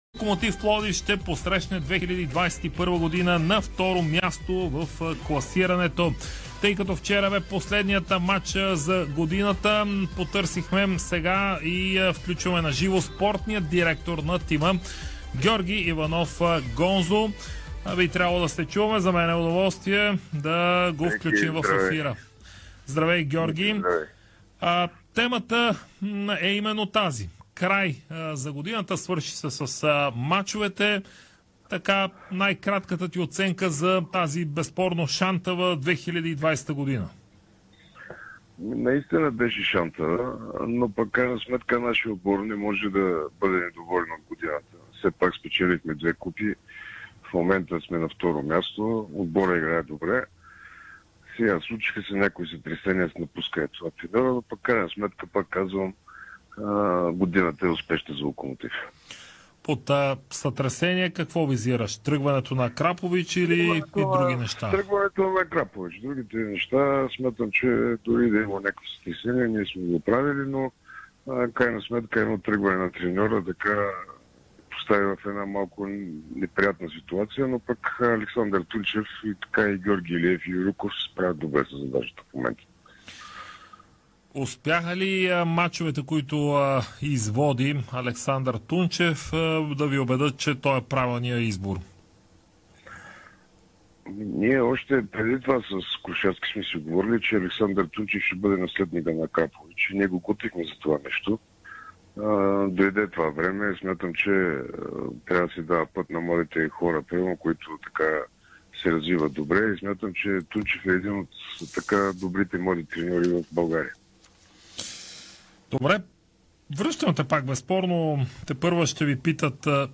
Спортният директор на Локомотив Пловдив Георги Ивано-Гонзо даде обширно интервю в ефира на Дарик радио, в което основно говори за ситуацията при „черно-белите“. Той обаче говори и за предстоящото дерби между ЦСКА и Левски, което ще се играе в петък.